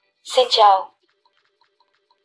Tiếng loa thông báo nói Xin Chào…!
Description: Tiếng loa phát thanh vang lên rõ ràng: “Xin chào…!”, âm thanh loa thông báo, còn gọi là hệ thống phát giọng, thiết bị truyền thanh hay loa công cộng, phát ra giọng nói thân thiện, mời gọi khách hàng, chào đón du khách.
tieng-loa-thong-bao-noi-xin-chao-www_tiengdong_com.mp3